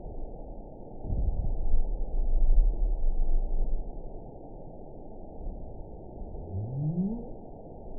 event 922807 date 04/08/25 time 18:46:49 GMT (2 months, 1 week ago) score 9.03 location TSS-AB04 detected by nrw target species NRW annotations +NRW Spectrogram: Frequency (kHz) vs. Time (s) audio not available .wav